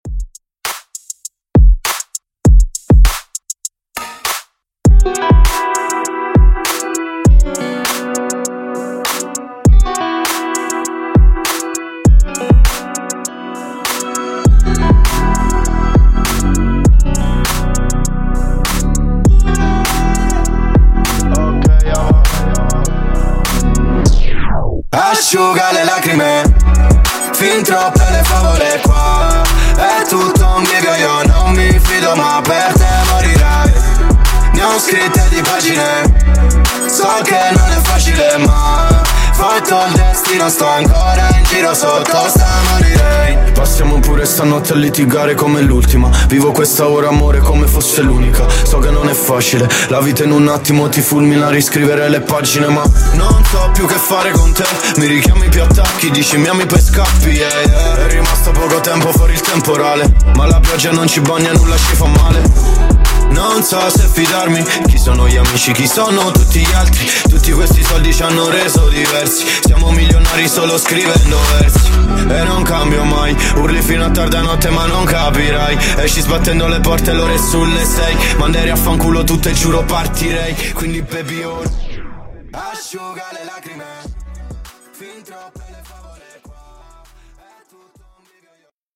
Genres: HIPHOP , RE-DRUM , TOP40
Dirty BPM: 100 Time